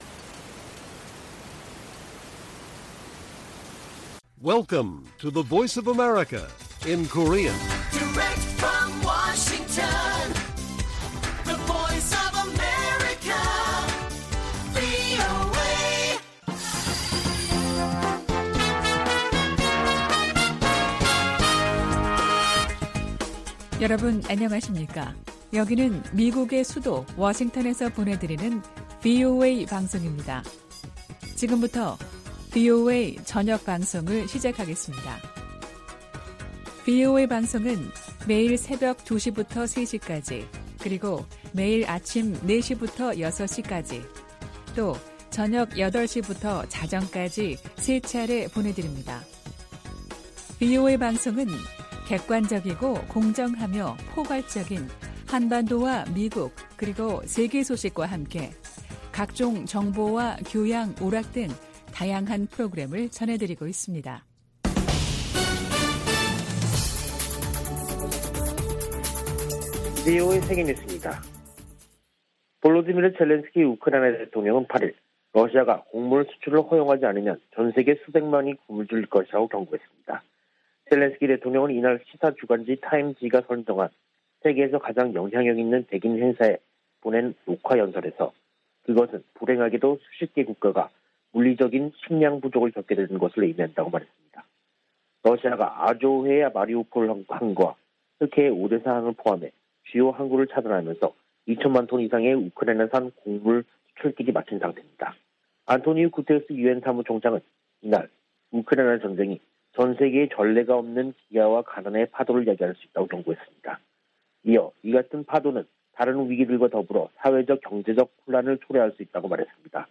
VOA 한국어 간판 뉴스 프로그램 '뉴스 투데이', 2022년 6월 9일 1부 방송입니다. 북한이 7차 핵실험을 강행하면 강력히 대응할 것이라는 방침을 백악관 고위당국자가 재확인했습니다. 미국의 B-1B 전략폭격기가 괌에 전진 배치됐습니다. 한국 외교부 김건 한반도평화교섭본부장과 중국의 류샤오밍 한반도사무특별대표가 전화로 최근 한반도 정세와 북한의 미사일 도발 대응 등에 의견을 교환했습니다.